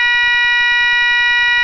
Sirena Multitonal 8 Sonidos
102dB